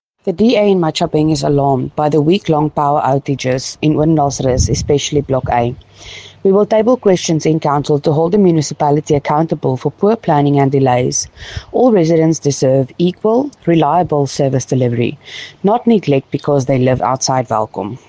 Afrikaans soundbites by Cllr Estelle Dansey and